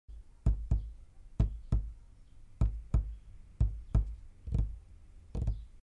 На этой странице собраны различные звуки постукивания пальцами: по столу, стеклу, дереву и другим поверхностям.
Постукивание пальцами - Прислушайтесь